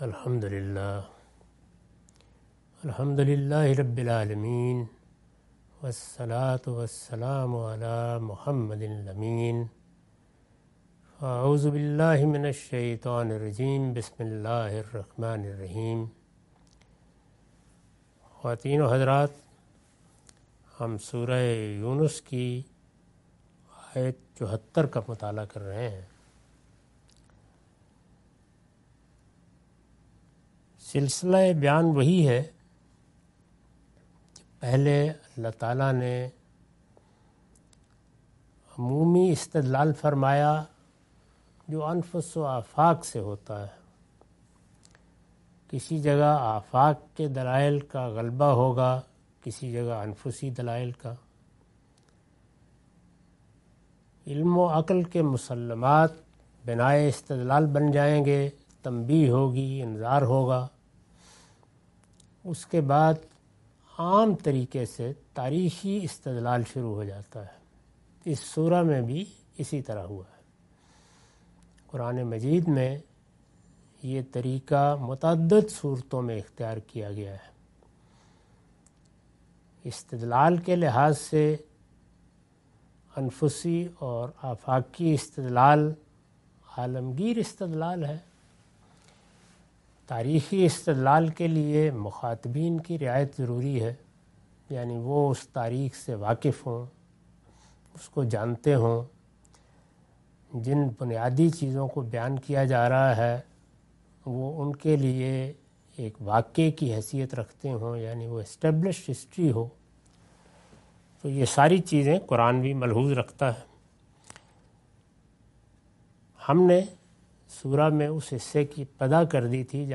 Surah Yunus- A lecture of Tafseer-ul-Quran – Al-Bayan by Javed Ahmad Ghamidi. Commentary and explanation of verses 74-78.